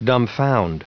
Prononciation du mot dumbfound en anglais (fichier audio)
Prononciation du mot : dumbfound